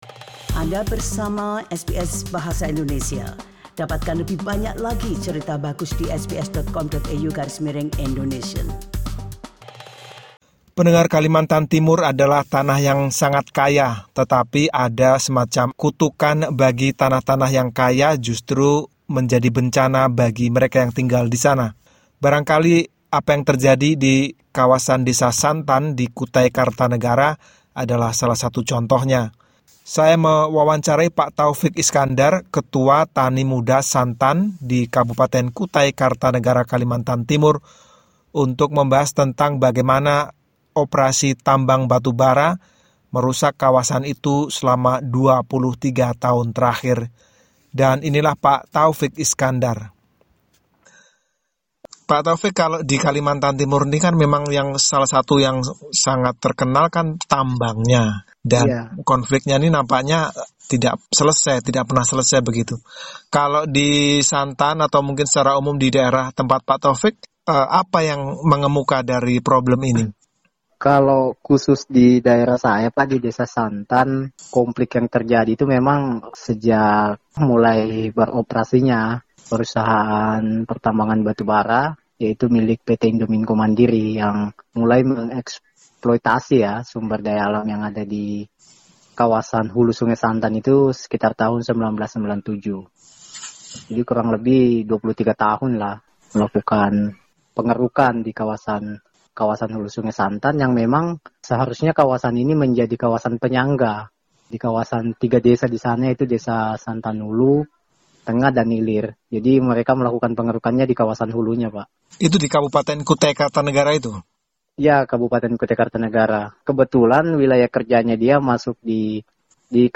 Dalam wawancara ini